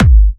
VEC3 Bassdrums Trance 28.wav